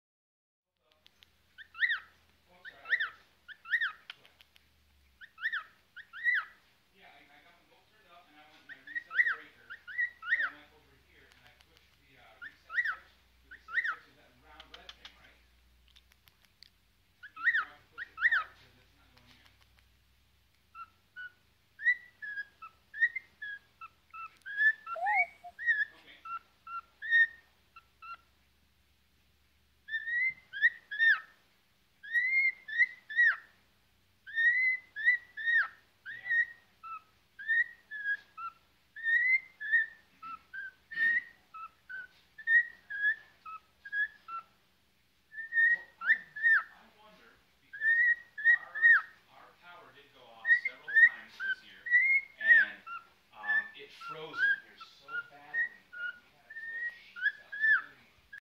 دانلود آهنگ عروس هلندی 4 از افکت صوتی انسان و موجودات زنده
جلوه های صوتی
دانلود صدای عروس هلندی 4 از ساعد نیوز با لینک مستقیم و کیفیت بالا